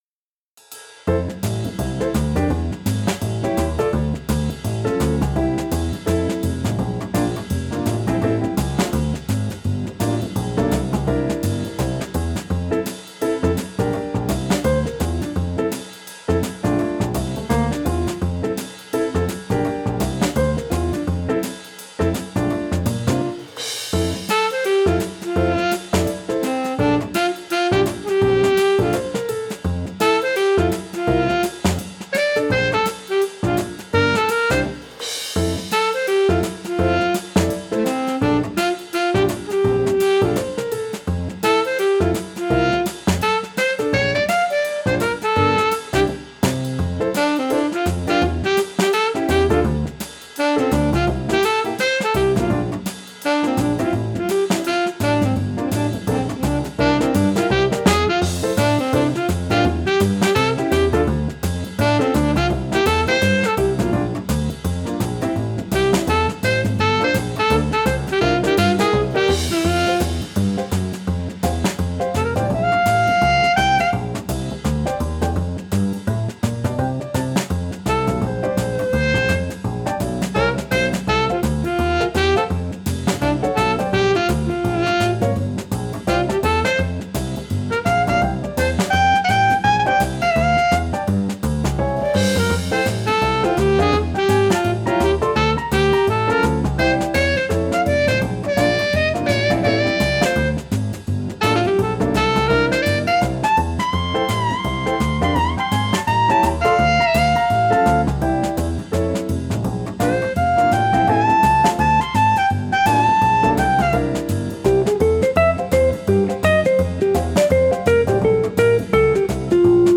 明るい曲調でゆるくスイングするジャズ風の曲です。 サックスとエレキギターがソロをとっています。